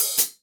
14HK OP.CL.wav